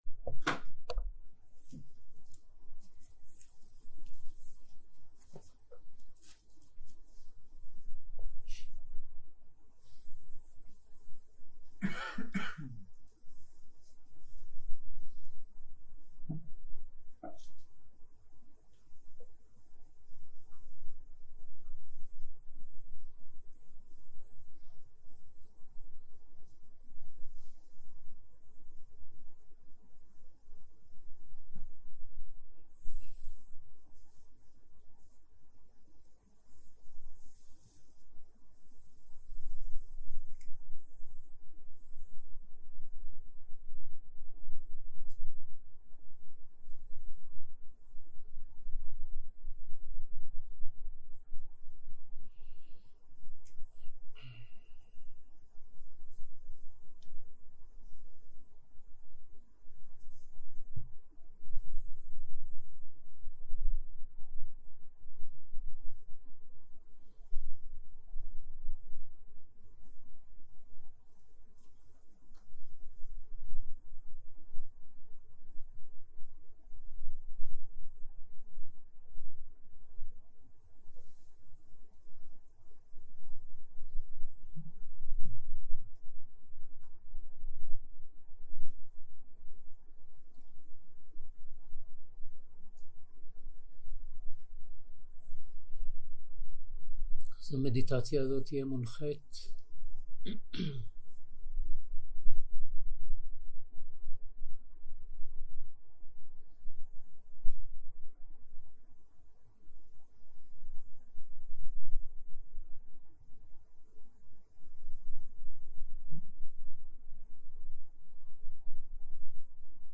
יום 3 - צהרים - מדיטציה מונחית - הקלטה 5
סוג ההקלטה: מדיטציה מונחית